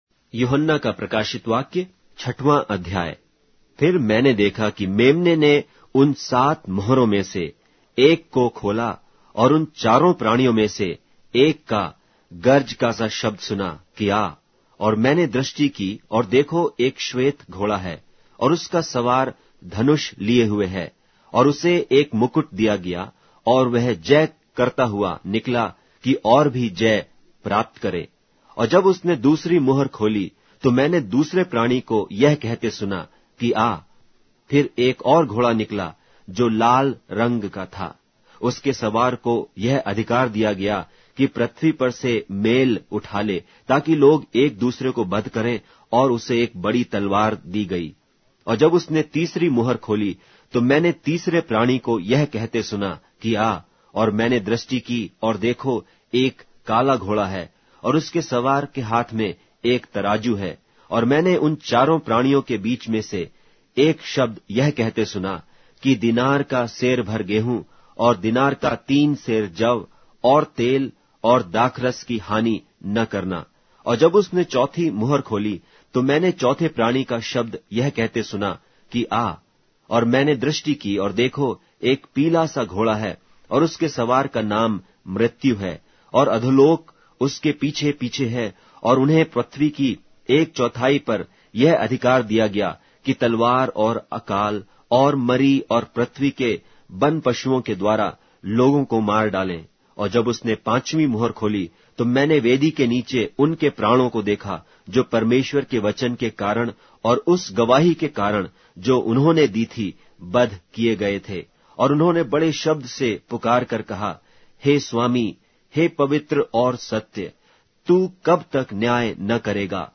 Hindi Audio Bible - Revelation 2 in Web bible version